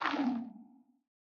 Minecraft Version Minecraft Version latest Latest Release | Latest Snapshot latest / assets / minecraft / sounds / block / sculk_sensor / sculk_clicking_stop5.ogg Compare With Compare With Latest Release | Latest Snapshot
sculk_clicking_stop5.ogg